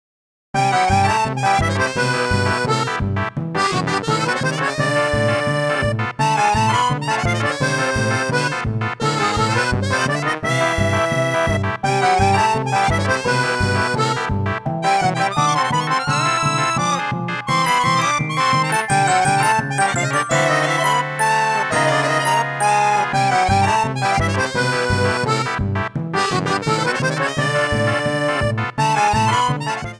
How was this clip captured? Trimmed file to 30 seconds, applied fadeout